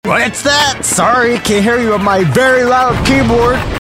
Sound Buttons: Sound Buttons View : Very Loud Keyboard
very-loud-keyboard.mp3